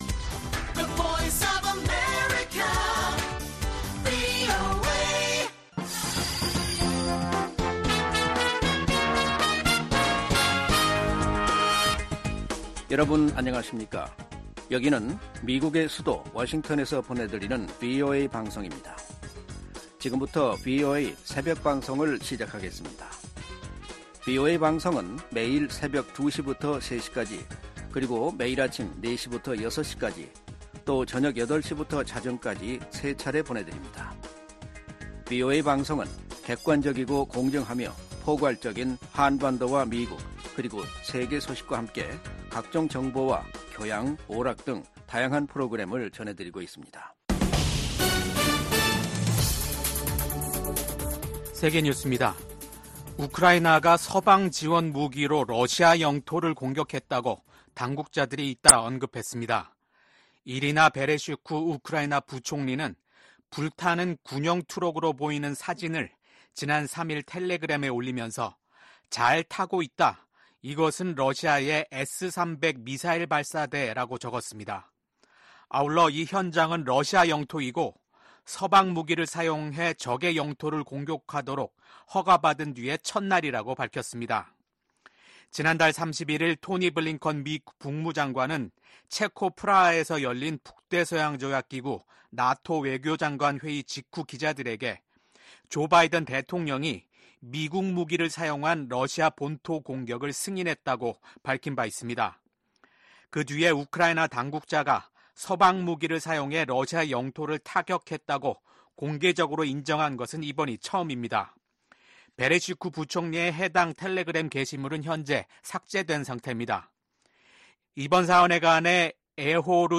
VOA 한국어 '출발 뉴스 쇼', 2024년 6월 6일 방송입니다. 미국, 한국, 일본이 국제원자력기구 IAEA 정기 이사회에서 북한과 러시아의 군사 협력 확대를 비판하며 즉각 중단할 것을 한목소리로 촉구했습니다. 백악관이 북한 정권의 대남 오물풍선 살포 등 도발과 관련해 큰 우려를 가지고 주시하고 있다며 평양이 불필요한 행동을 계속하고 있다고 비판했습니다.